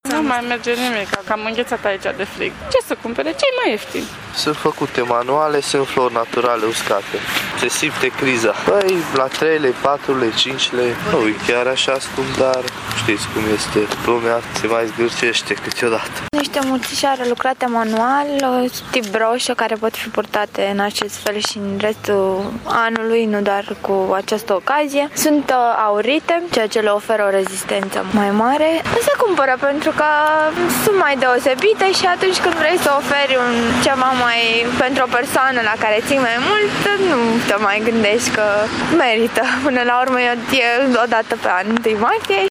Deși este unul din cele mai cumpărate produse în această perioadă, comercianţii se plâng că vânzările sunt tot mai slabe de la un an la altul şi că oamenii se uită întâi la preţ şi abia apoi la calitate: